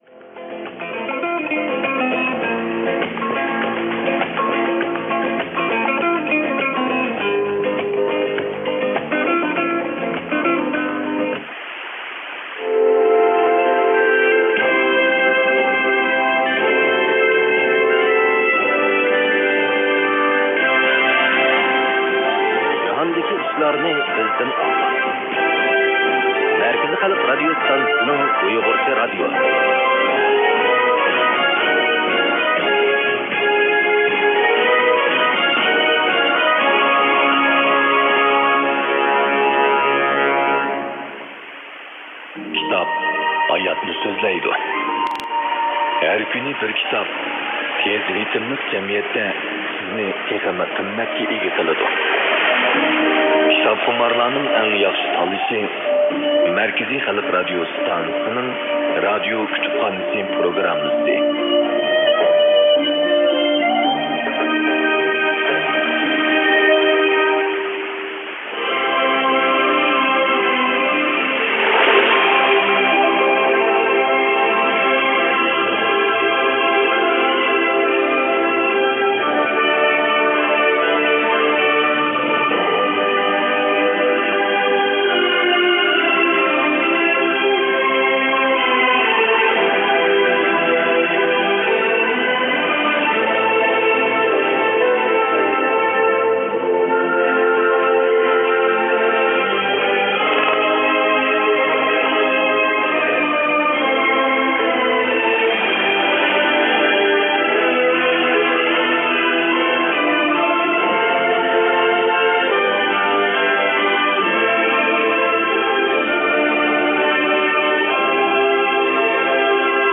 もしかしたら "CNR13 维吾尔語広播"のCanned IDのところのような気がしてきました。古いですが2022年4月17日のパラで短波で放送しているものを録音したものを参考に添付してみます。